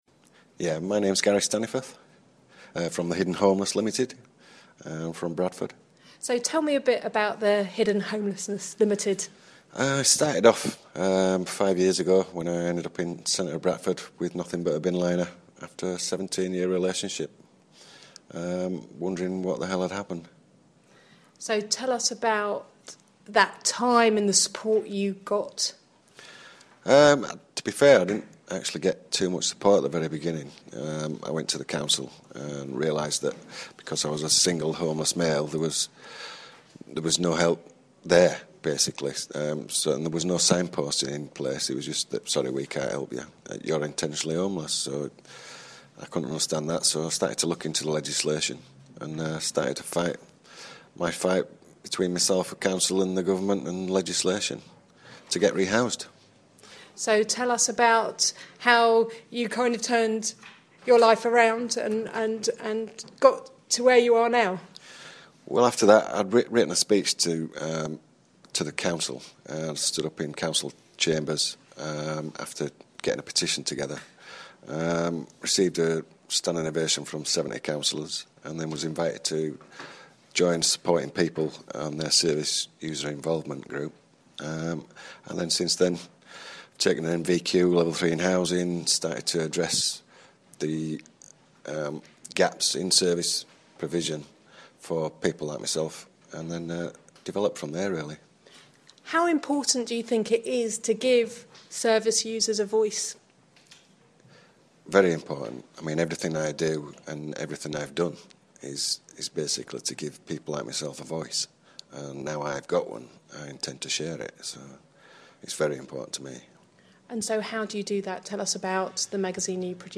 In this short interview